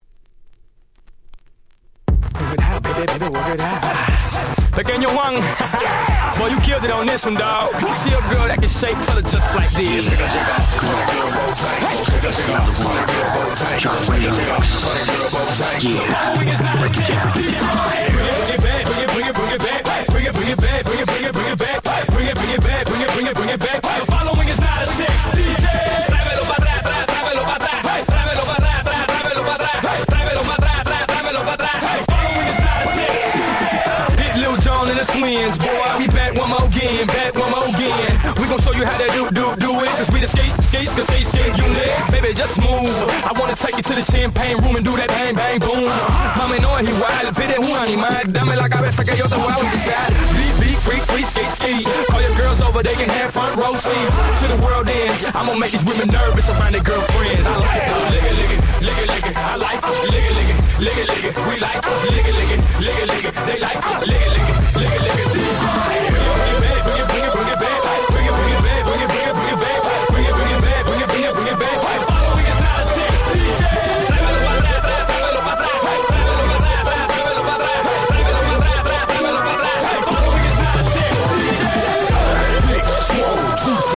全6曲全部アゲアゲ！！